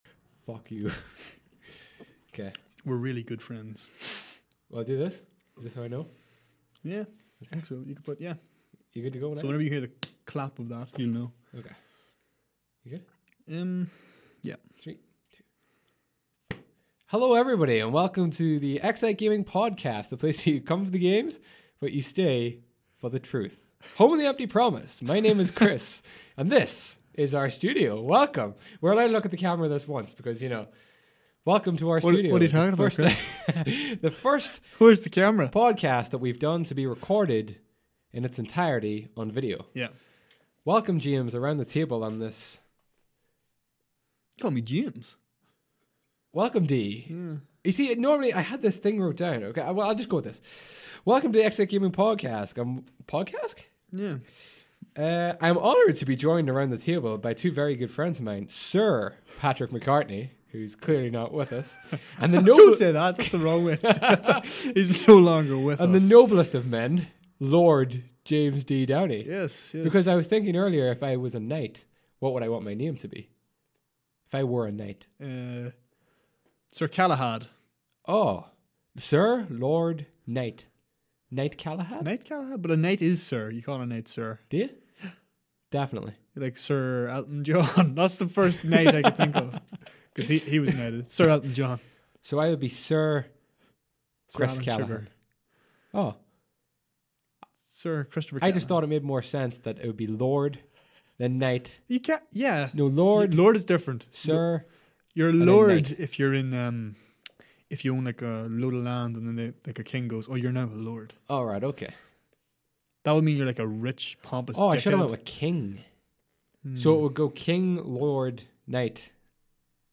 Video and Audio quality WILL improve over the forthcoming weeks but until then… Enjoy.